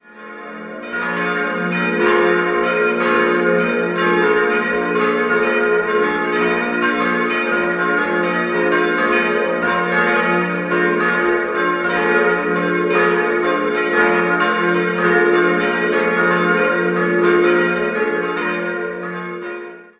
Die Pfarrkirche mit dem wuchtigen Zwiebelturm stammt im Kern noch aus dem 15. Jahrhundert. Im Jahr 1840 erfolgte ein Umbau. 4-stimmiges Geläut: f'-as'-b'-c'' Die kleine Glocke stammt noch aus dem 16.